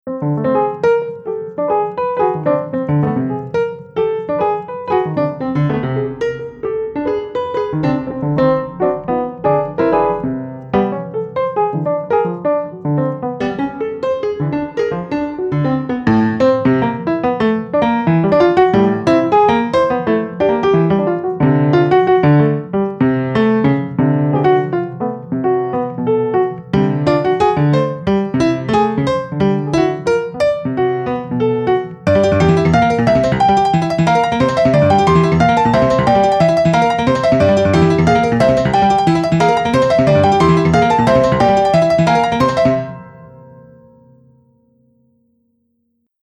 Have been wanting to do an all-piano release -- this may be the first stab.
This is actually me playing the piano, heavily edited. For some reason when I sit down and freestyle it comes out sounding like syncopated jazz chords. The ending, however, is pure MIDI piano roll.